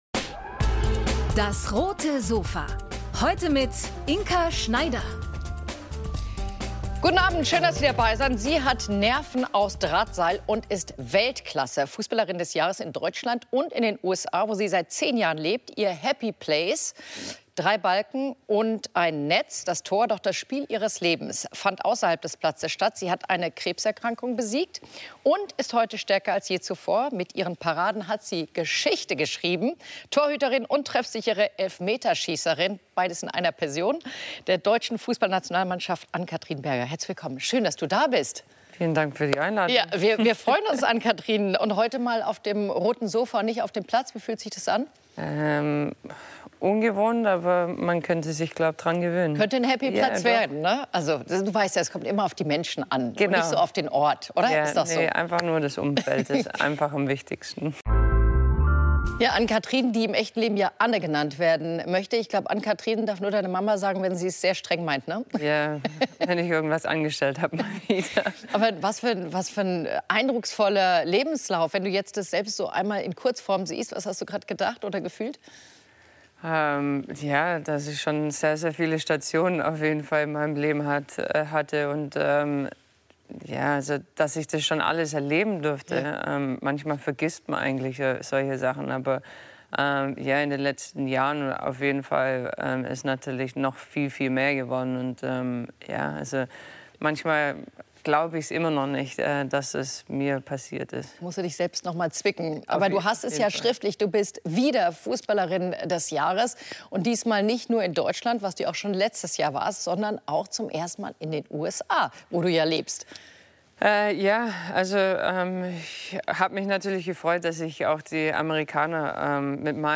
Bei uns wird die frische US-Meisterin über ihre erstaunlichen Karriere, aber auch über ihr größtes Tief, ihre Krebs-Diagnose sprechen und den Weg zurück an die Spitze.